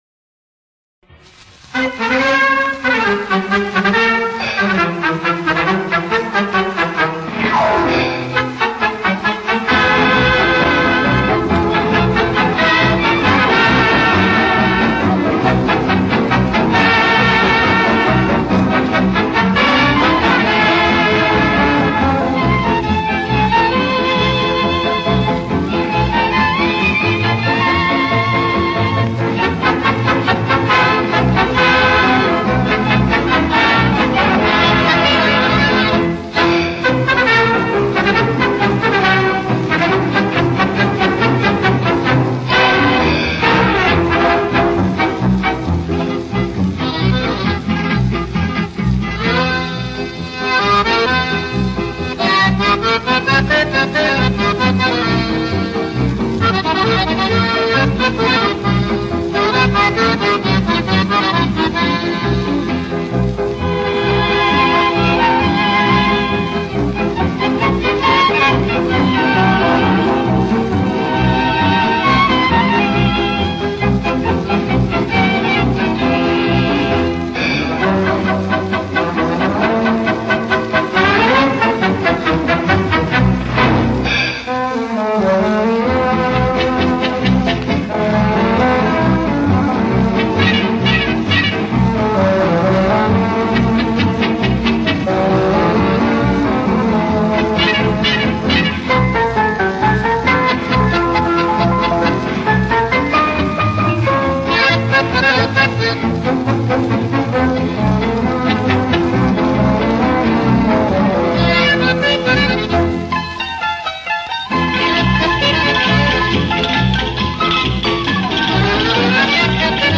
Польский оркестр - Терезина (Ещё один пасадобль от создателя Рио-Риты - композитора Энрико Сантагини, запись 1930-х годов)